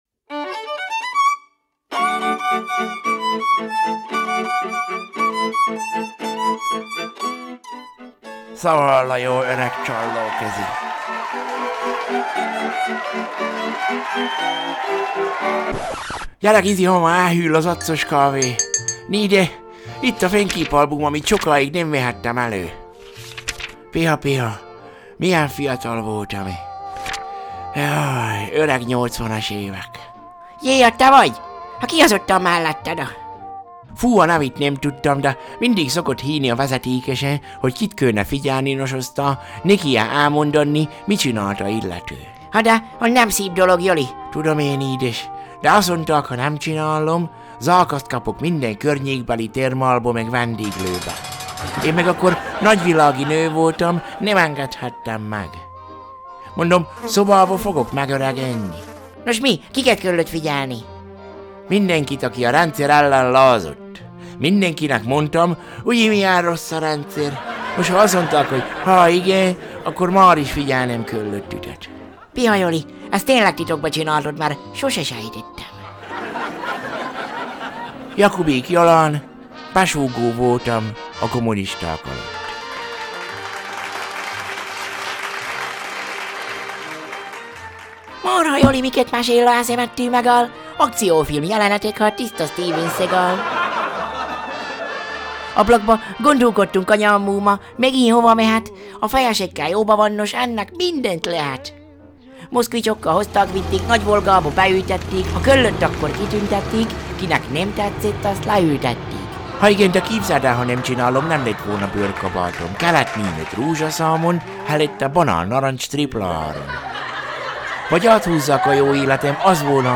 Zene:
Pósfa zenekar – Ó, én édes komámasszony
Nena – 99 luftballons